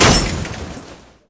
damage50_2.ogg